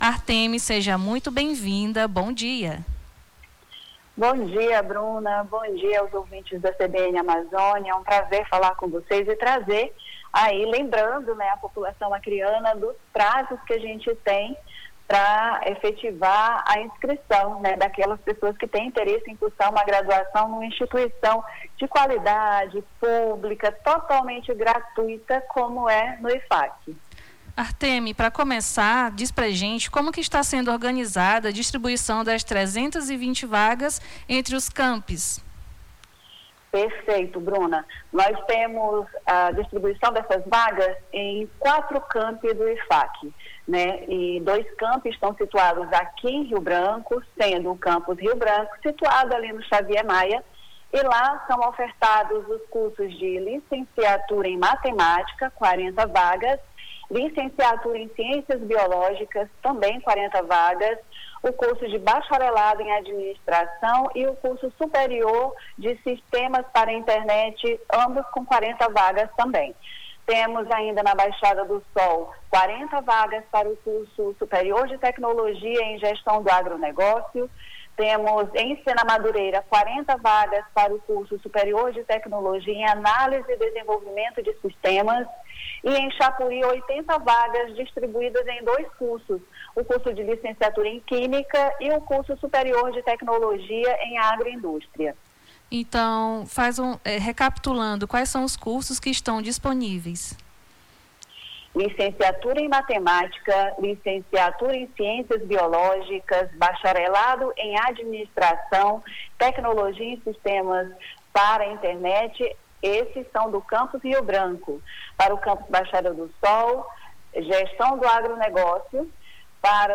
Nome do Artista - CENSURA - ENTREVISTA (CURSOS IFAC) 16-01-26.mp3